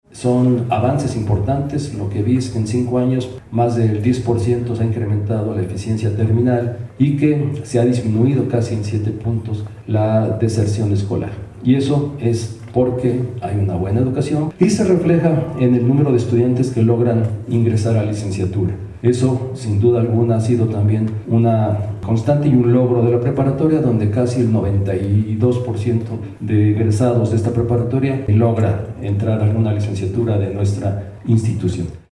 Rector informe preparatoria Lázaro Cárdenas.mp3